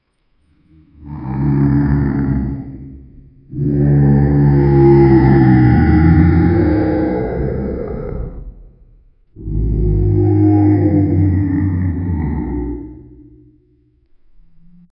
Guttural groan, groaning monster. Deep echo moan. Scary.
guttural-groan-groaning-m-5ik2ibzz.wav